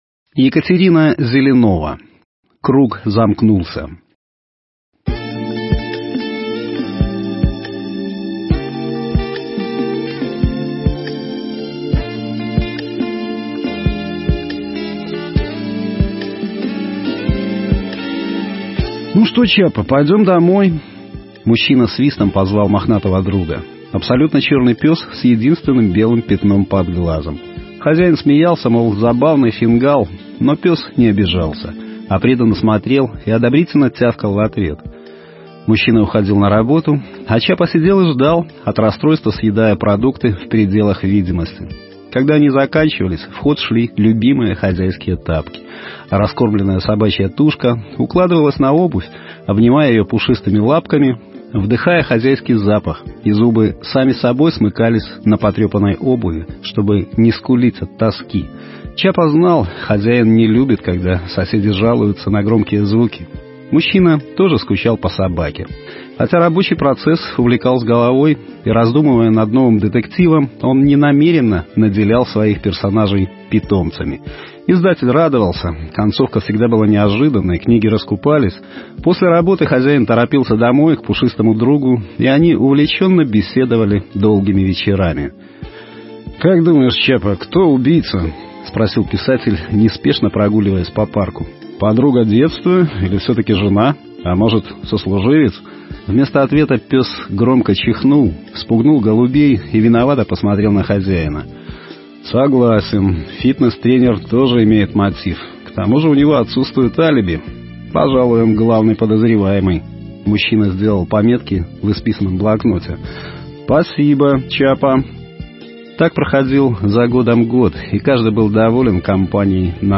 Аудио-Рассказы